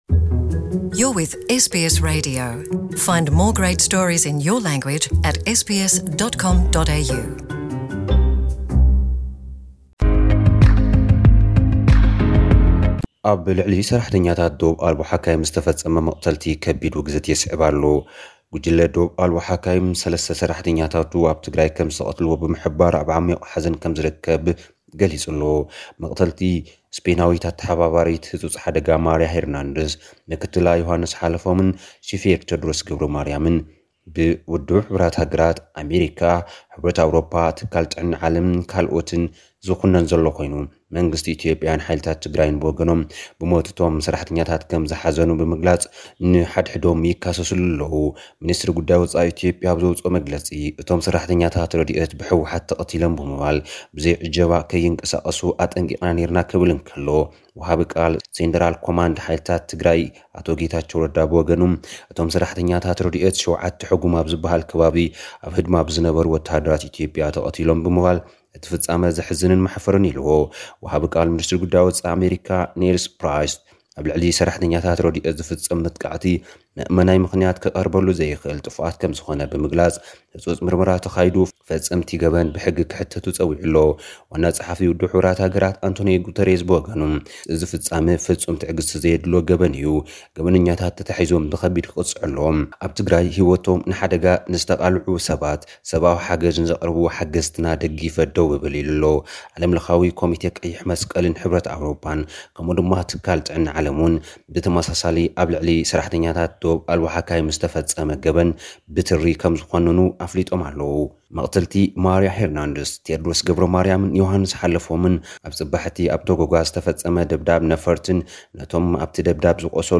ጸብጻብ ዜናታት ንማሕበረሰብ ዓለም ዘሰምበደ ቅትለት ሰራሕተኛታት ረድኤት ኣብ ትግራይ፣ ሚንስትሪ ጉዳይ ወጺኢ ኣሜሪካ ኣንቶኒ ብሊንከን ንጉዳይ ትግራይ ሓዊሱ ኣብ ዝተፈላለዩ ጉዳያት ምስ ትካል መግቢ ዓለምን ካልኦትን ምይይጥ ኣሳሊጡ ዝብሉን ካልኦትን